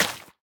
1.21.4 / assets / minecraft / sounds / dig / wet_grass2.ogg
wet_grass2.ogg